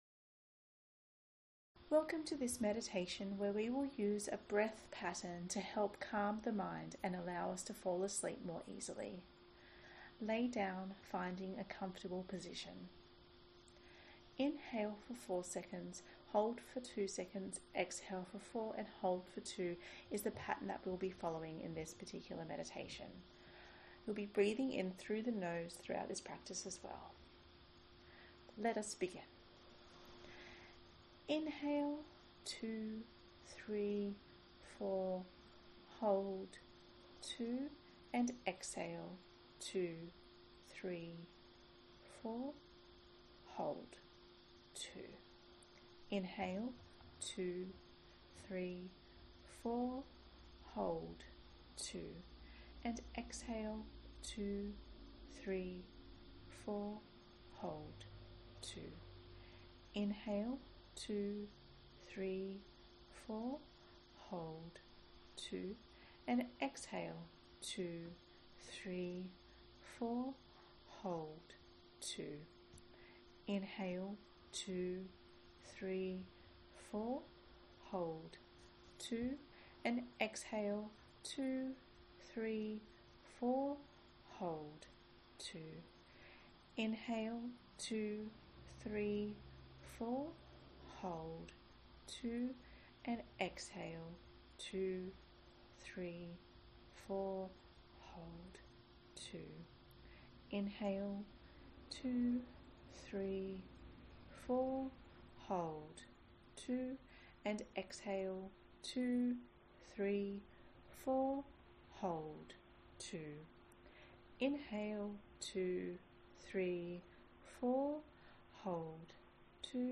4-2-4-2-Sleep-Meditation.mp3